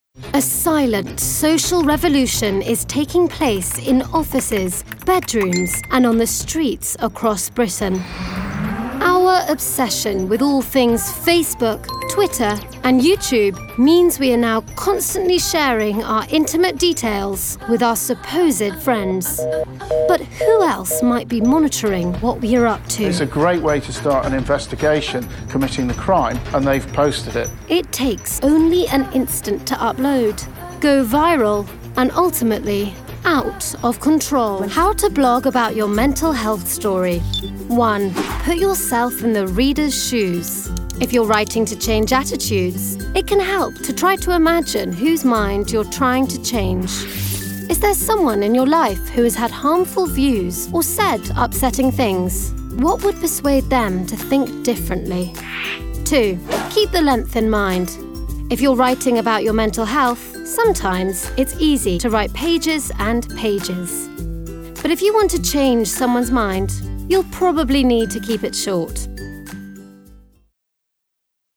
• Native Accent: RP